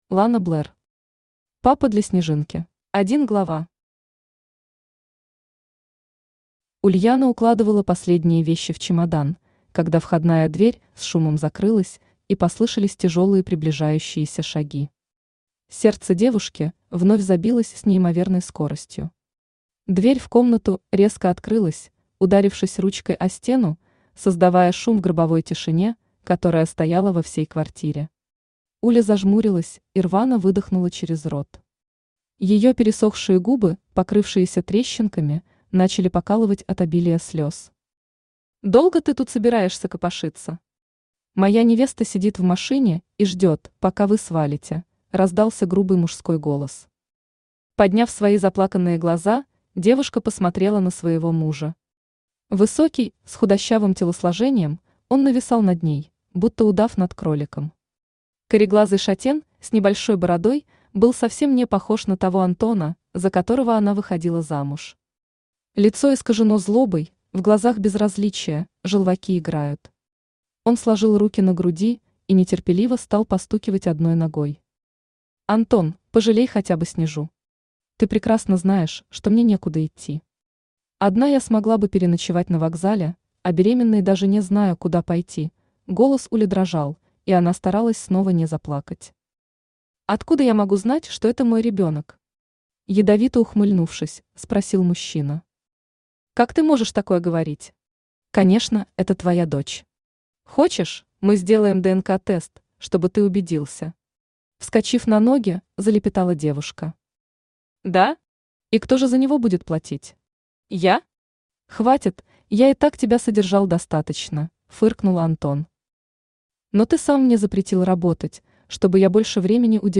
Аудиокнига Папа для Снежинки | Библиотека аудиокниг
Aудиокнига Папа для Снежинки Автор Лана Блэр Читает аудиокнигу Авточтец ЛитРес.